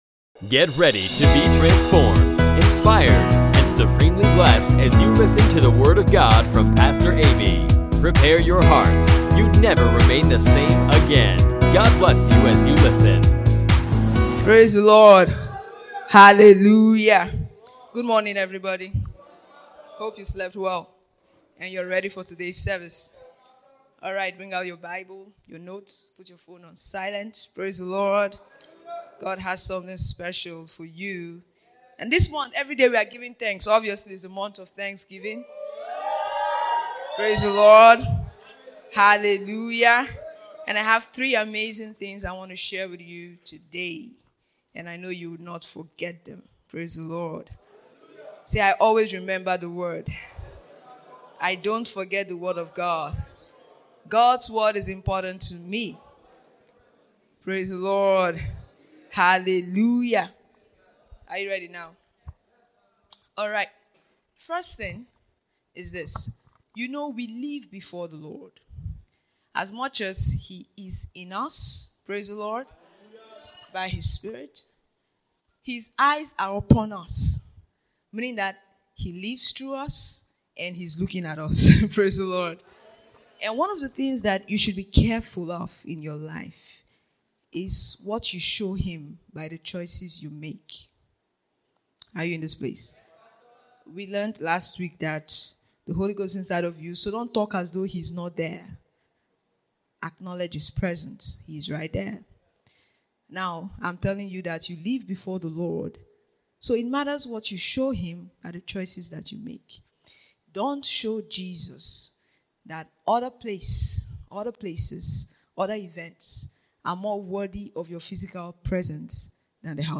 Your choices reveal your beliefs. Learn why you should prioritize the house of God, serve God and respond to him as though you’re the only one and why he gave you his word in this insightful teaching by Pastor.